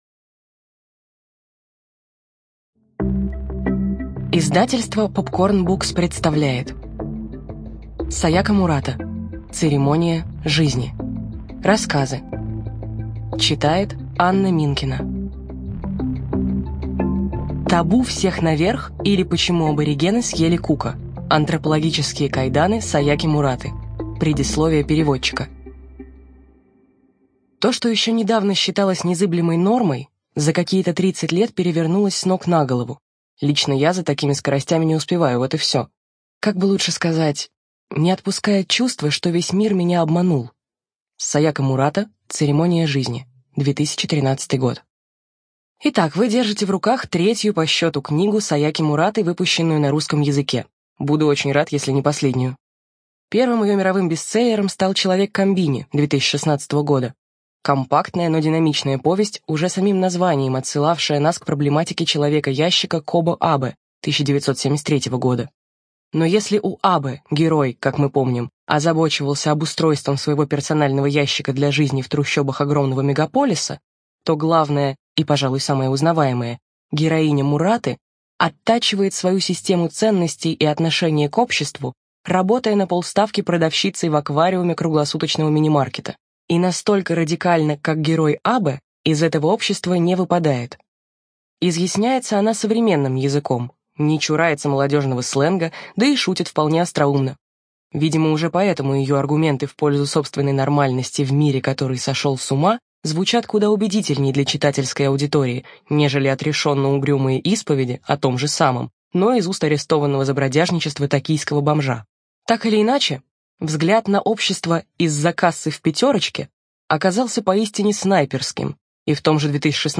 ЖанрСовременная проза